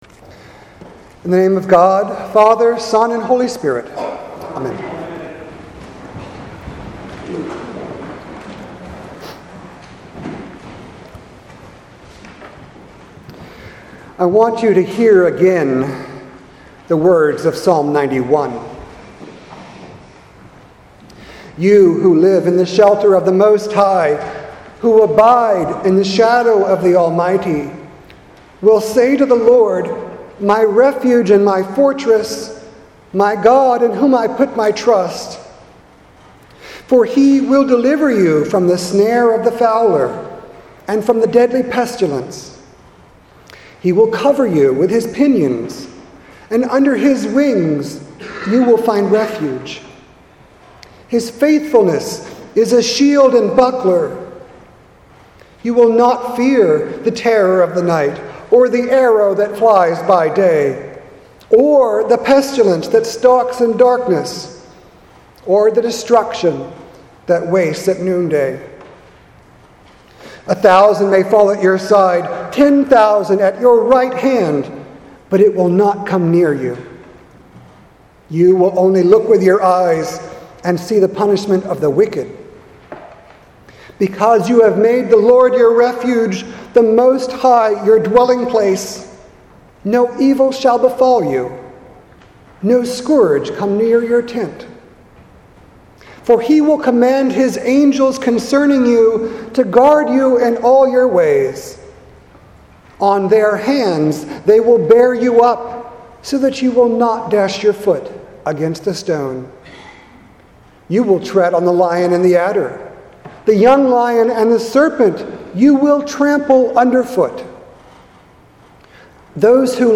Sermon for the First Sunday in Lent, Sunday, March 10th 2019.